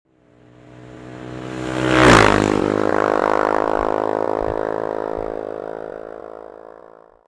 Motocicletta che passa
Rumore di motocicletta di media cilindrata che passa.
Motorbike_01.mp3